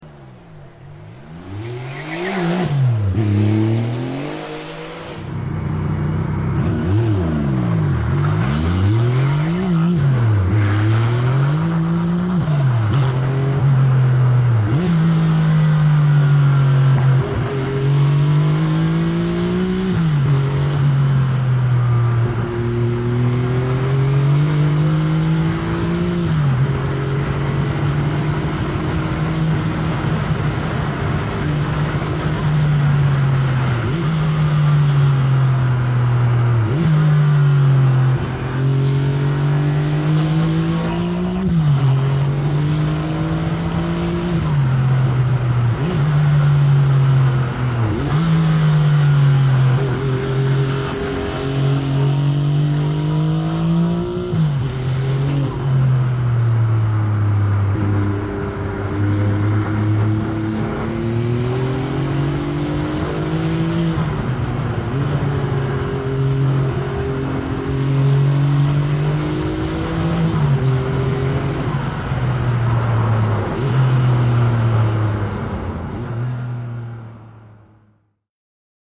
The Hillclimb Runs At Goodwood Sound Effects Free Download